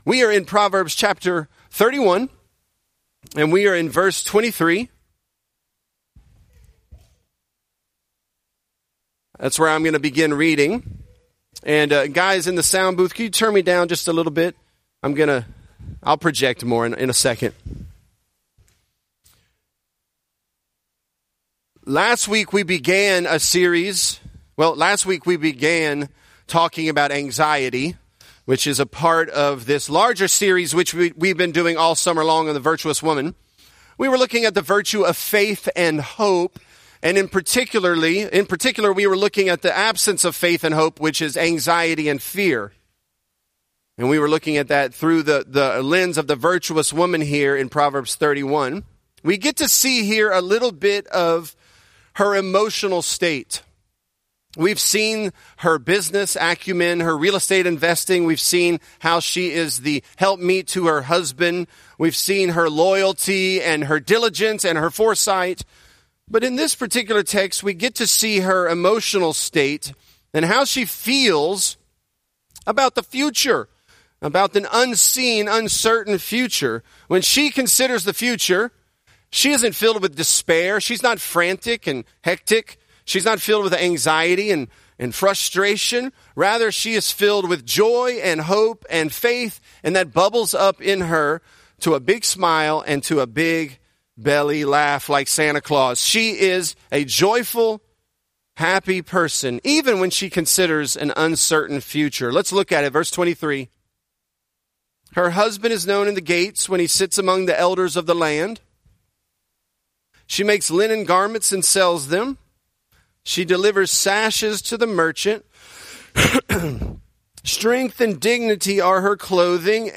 Virtuous: She Laughs at The Time to Come, Pt. II | Lafayette - Sermon (Proverbs 31)